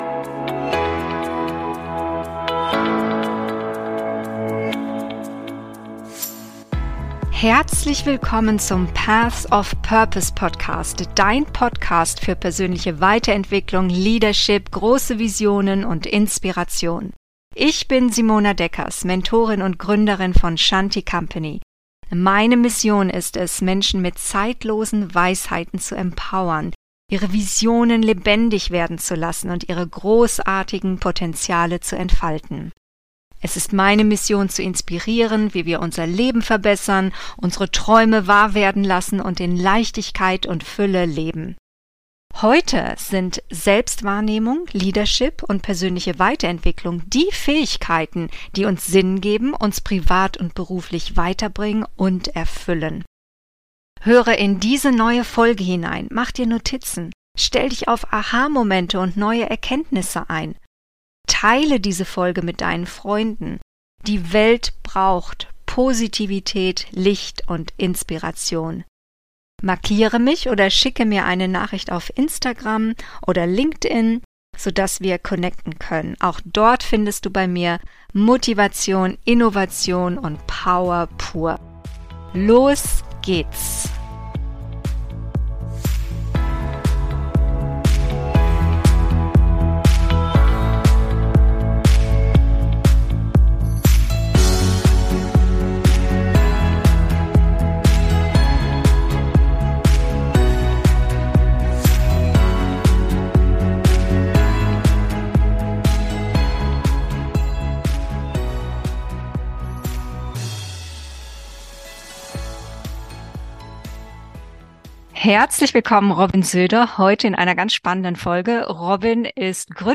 On Fire & Unstoppable – Interview